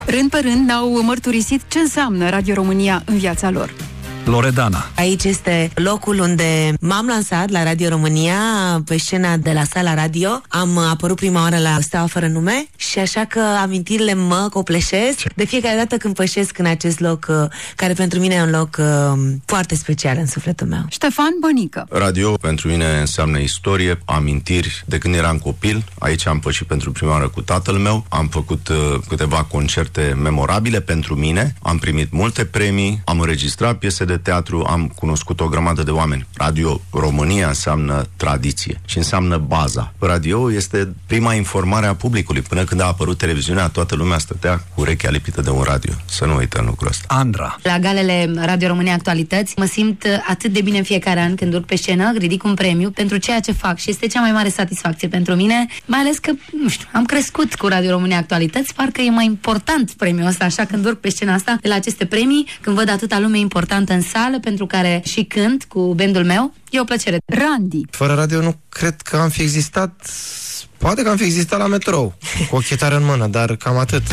La peste 9 decenii de la acel moment, vedete ale muzicii ușoare românești mărturisesc ce înseamnă Radio România în viața lor:
vedete-la-RRA.mp3